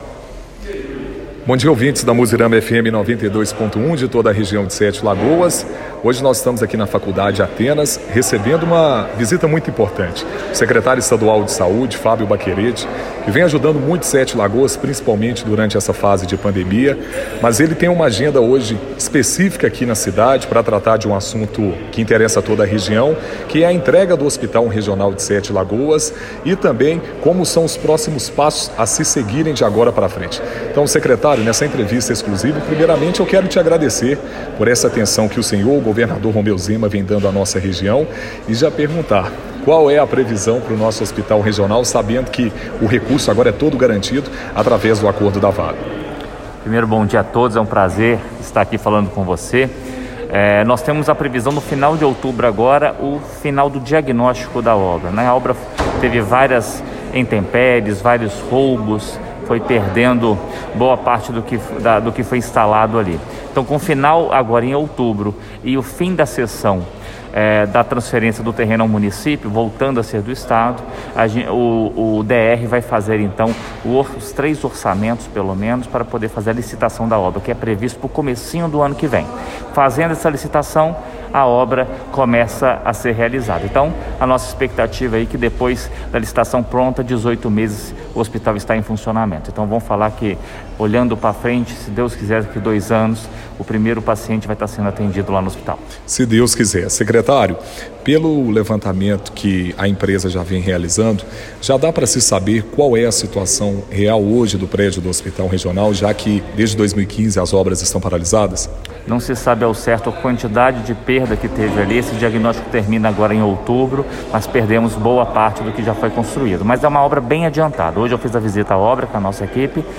entrevista-4